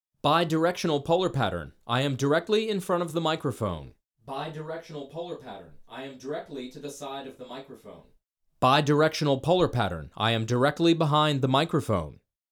BIDIRECTIONAL (FIGURE-EIGHT)
vocals-bidirectional-waveinformer.mp3